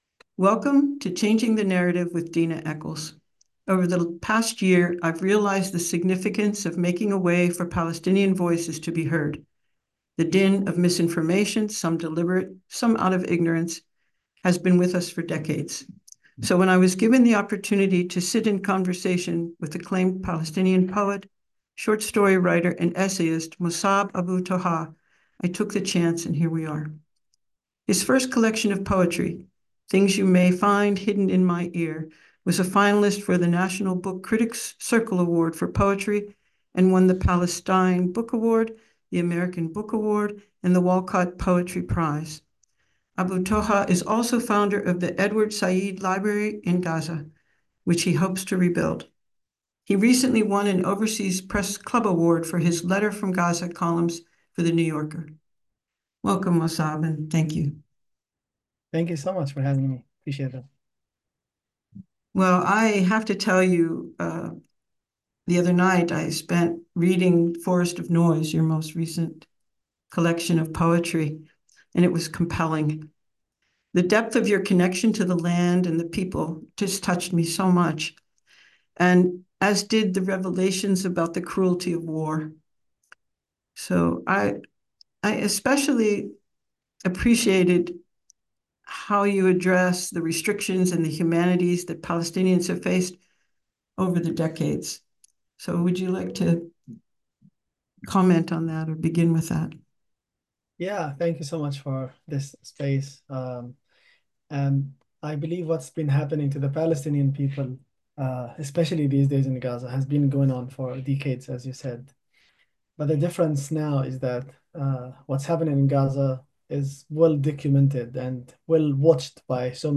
An Interview with Mosab Abu Toha - WDRT